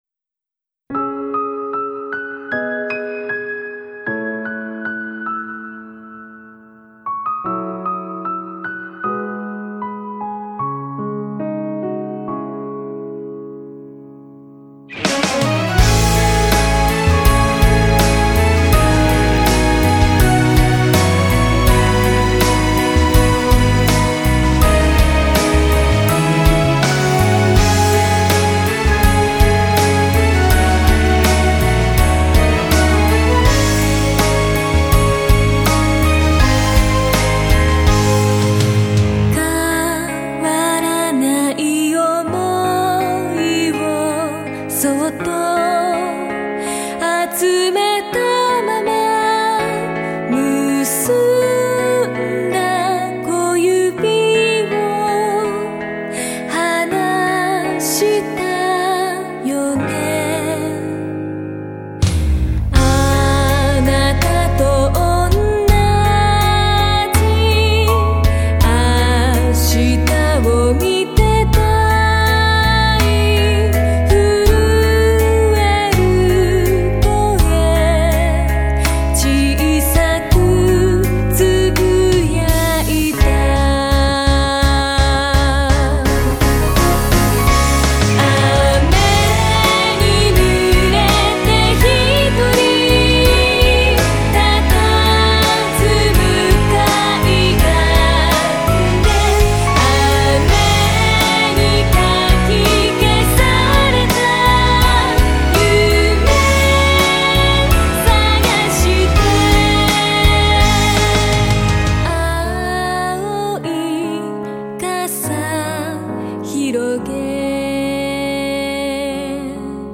ＯＰテーマ